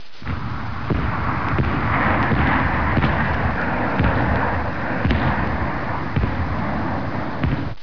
دانلود آهنگ طیاره 39 از افکت صوتی حمل و نقل
جلوه های صوتی